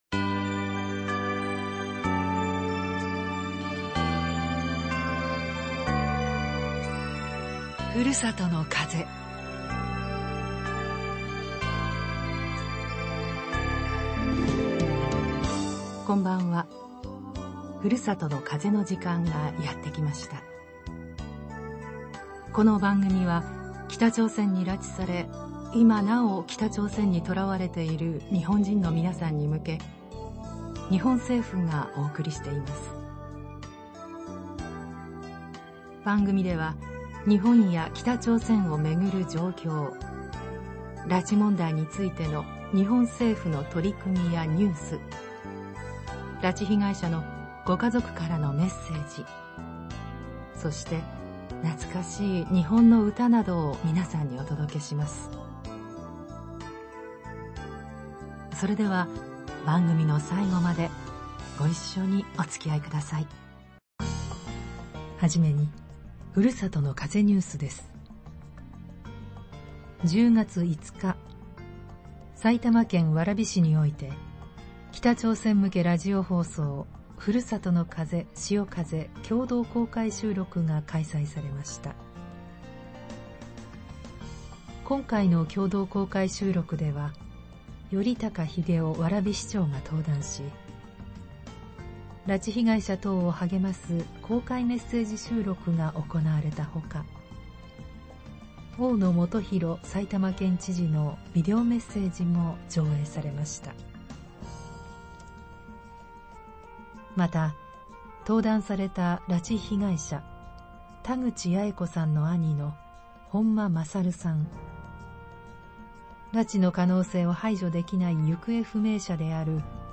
北朝鮮向けラジオ放送「ふるさとの風」「しおかぜ」共同公開収録 in 蕨 （令和6年10月5日開催） 政府と民間団体の特定失踪者問題調査会は、北朝鮮に拉致され、今なお囚われている被害者の方々に向けて、ラジオ放送で毎日メッセージを送っています。 このラジオで放送するため、政府が運営する「ふるさとの風」と特定失踪者問題調査会が運営する「しおかぜ」が共同で、メッセージや歌声等を公開で収録するイベント、『北朝鮮向けラジオ放送「ふるさとの風」「しおかぜ」共同公開収録 ～希望の光、届け海を越えて！～ 』を蕨市で開催しました。